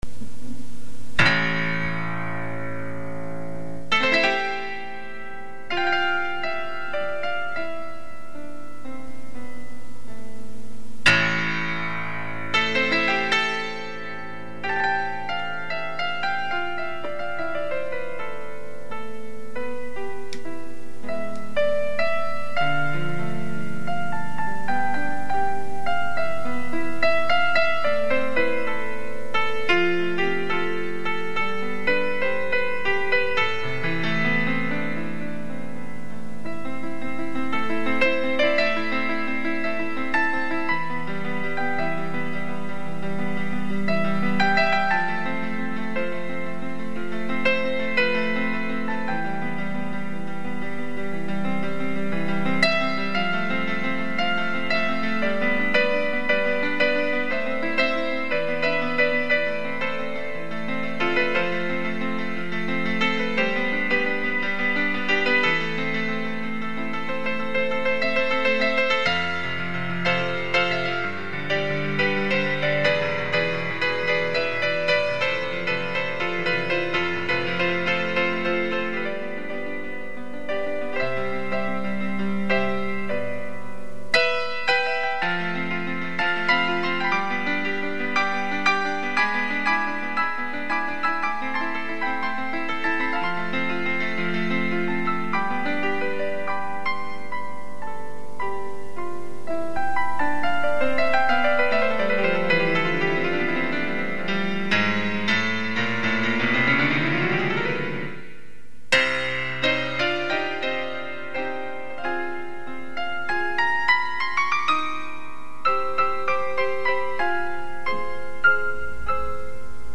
下宿のへっぽこピアノに小型テープレコーダーで録音した
素人の遊びです。
ただ思うまま和音を押してるだけなんですけど、